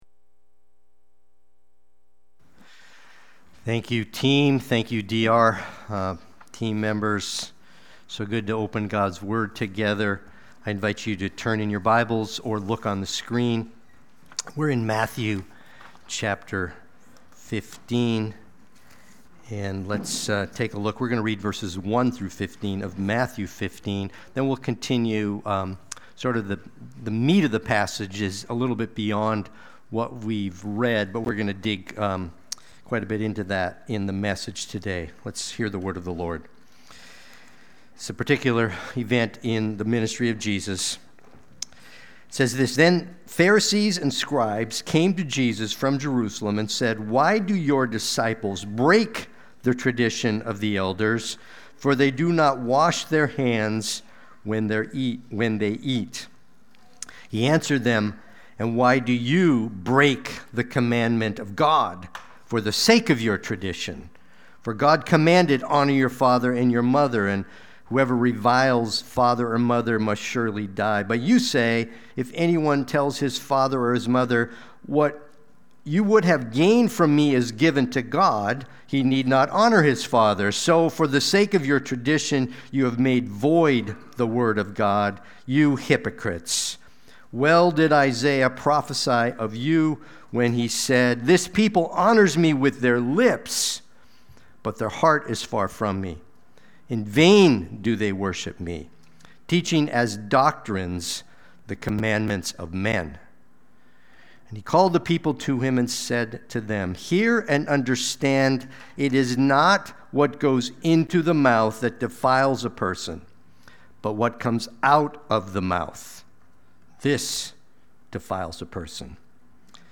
Note: due to technical difficulties, there is a moment of blank screen and audio in the replay prior to the beginning of the sermon.
Watch the replay or listen to the sermon.
Sunday-Worship-main-71325.mp3